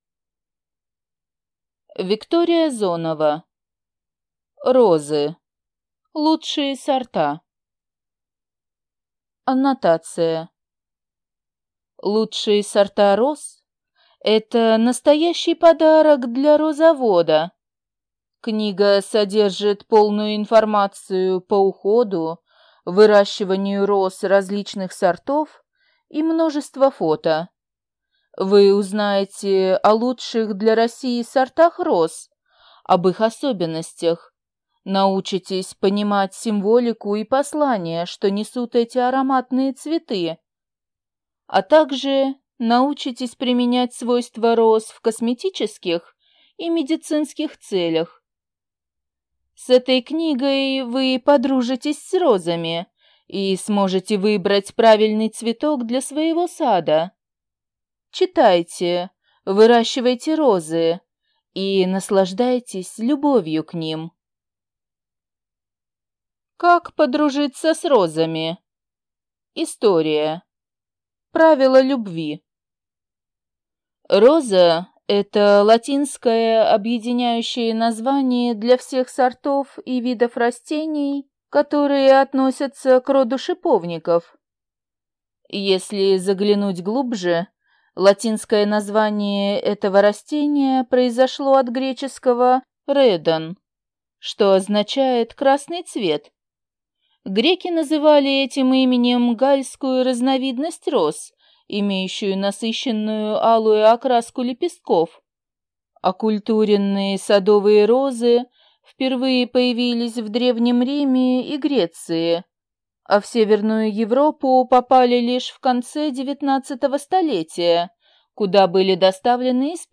Аудиокнига Розы. Лучшие сорта | Библиотека аудиокниг